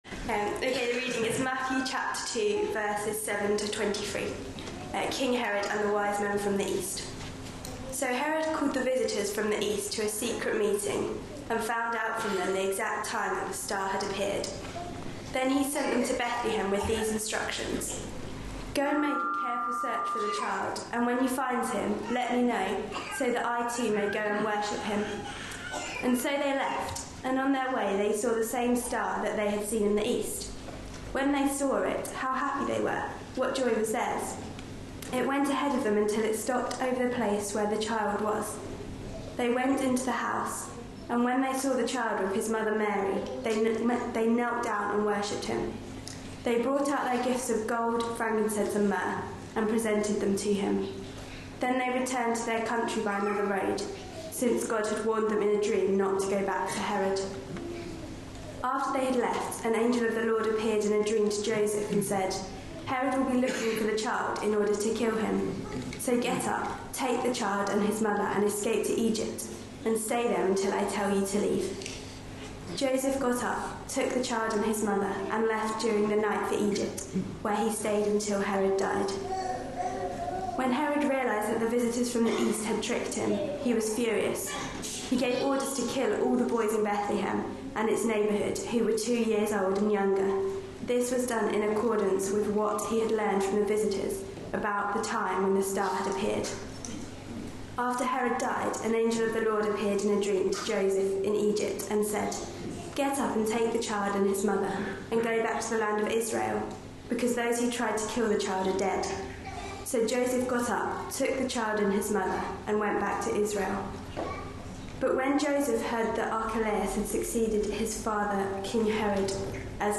A sermon preached on 28th December, 2014.
Matthew 2:7-23 Listen online Details Reading is Matthew 2:7-16 & 19-23b. This was a family service with a 'pantomime' theme, in keeping with the time of year (also a short clip from the movie 'Frozen' was shown earlier in the service), though with references to the ongoing conflicts in the Middle East.